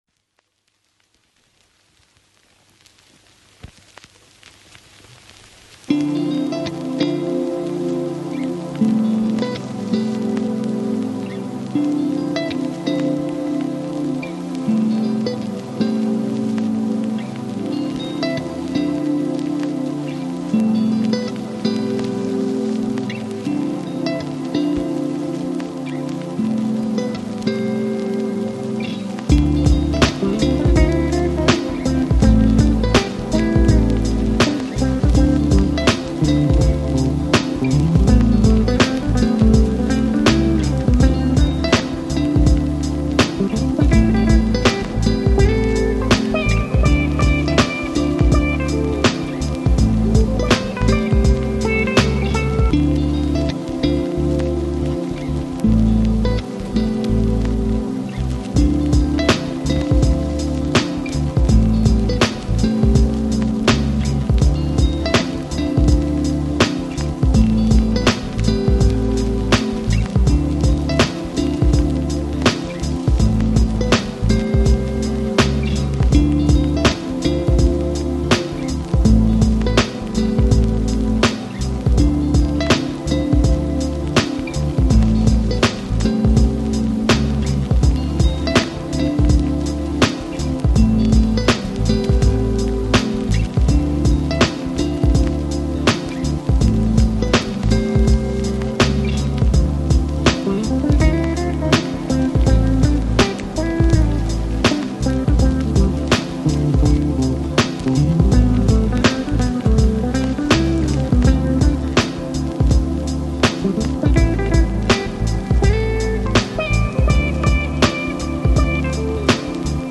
Жанр: Chill Out, Smooth Jazz, Downtempo